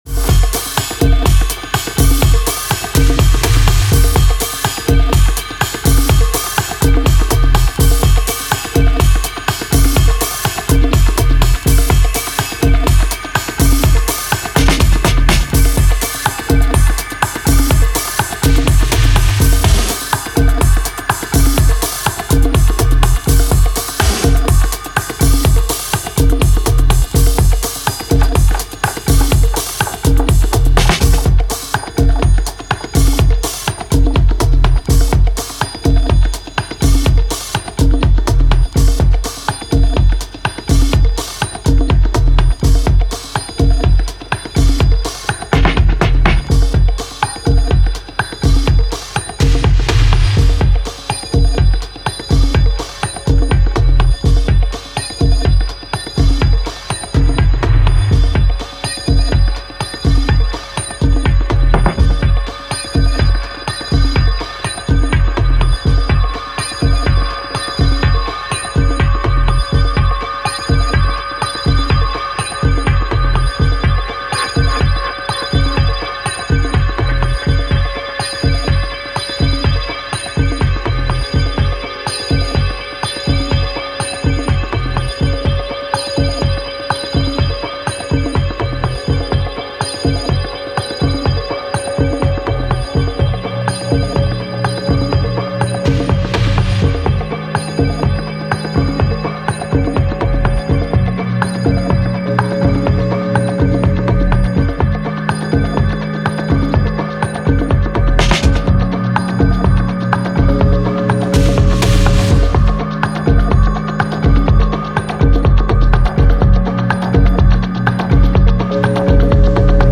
パーカッションとチャイムが遊び心のあるやり取りを繰り返しながら、ミッドテンポのグルーヴに落ち着いていく